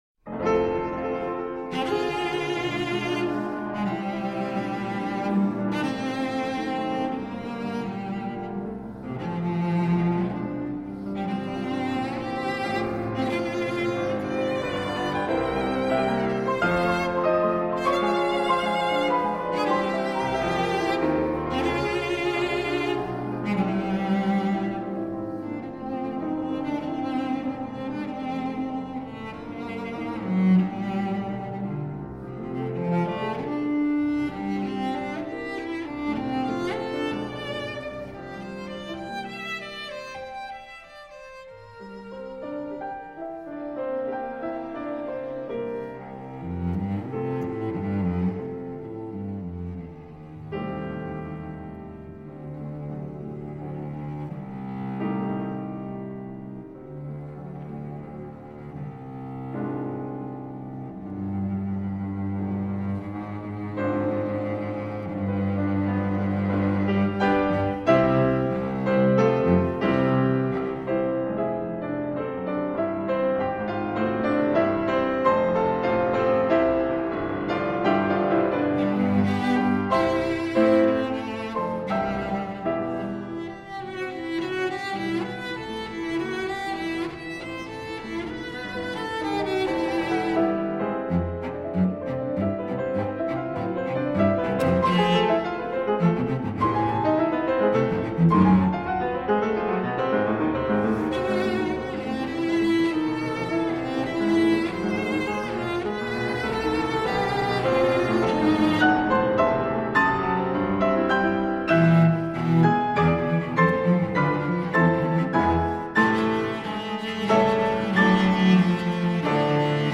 Cello sonata